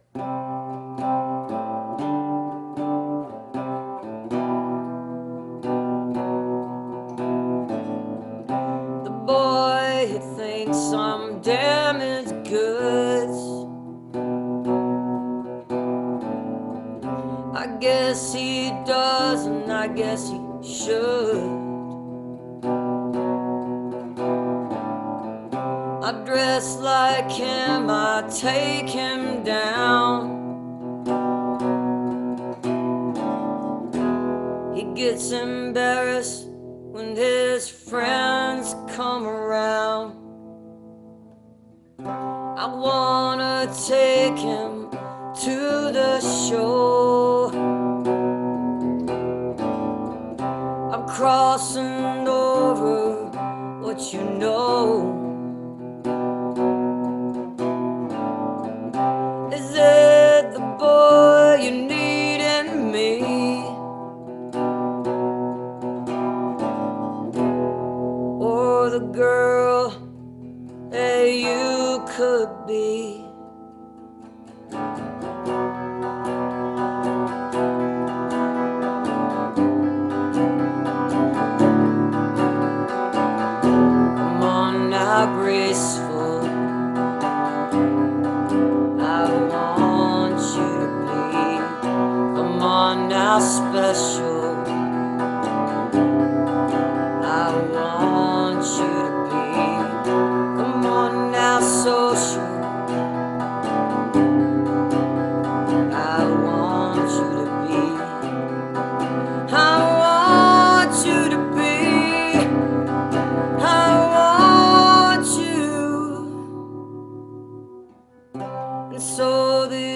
(captured from the live video stream)